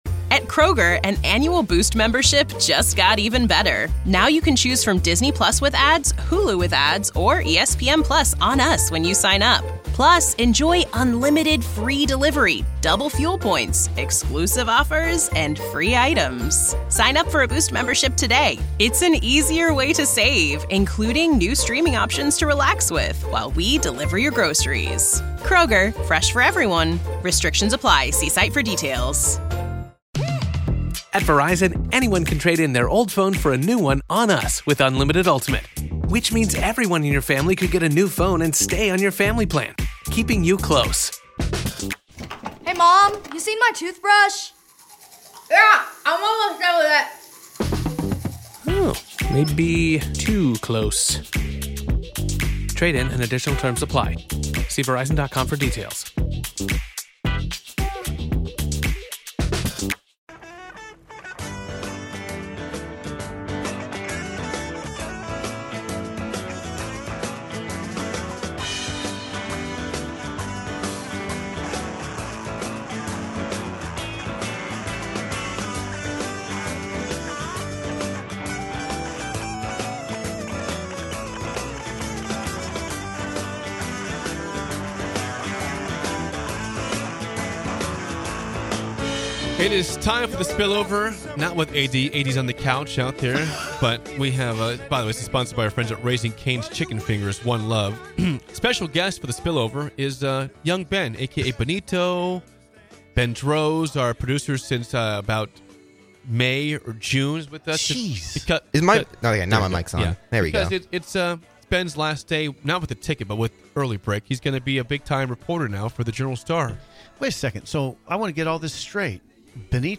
Join these three goofballs from 6-8am every weekday morning for the most upbeat and energetic morning show you'll ever experience.